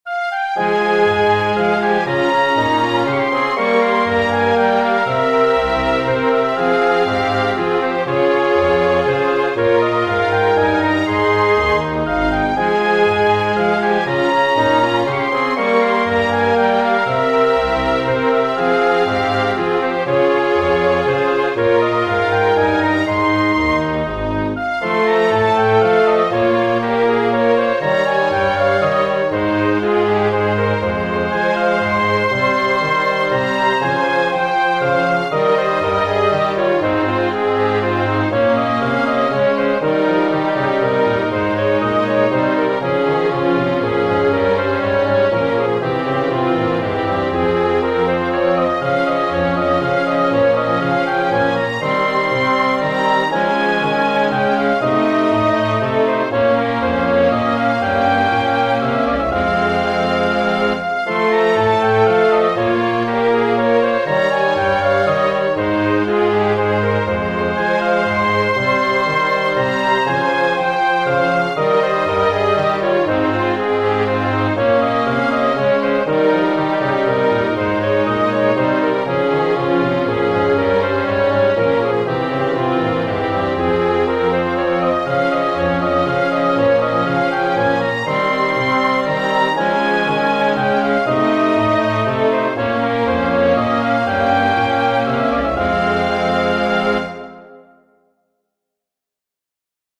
Baroque Orchestra idea - Chamber Music - Young Composers Music Forum
I have it on the piano score for now and will arrange it when I have the time.